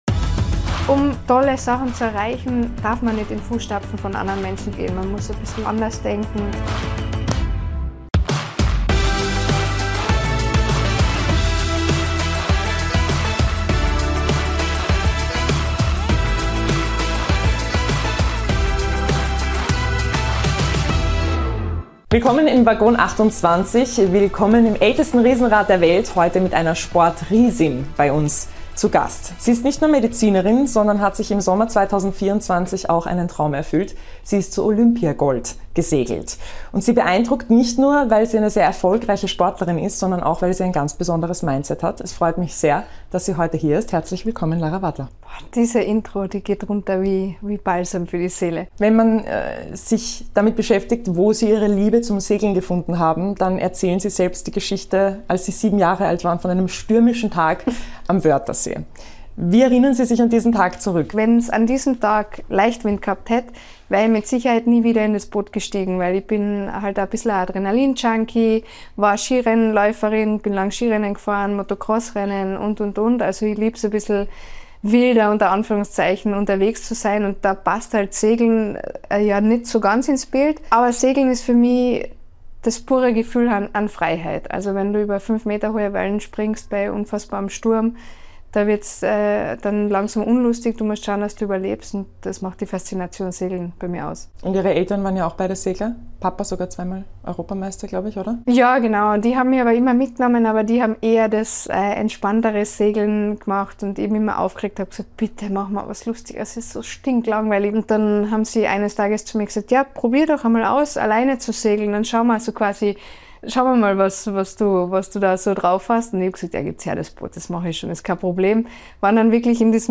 Im Sporttalk spricht die 31-Jährige u.a. über ihre erste Segelerfahrung, ihren Olympiasieg, ihr Buch sowie die olympischen Spiele 2028.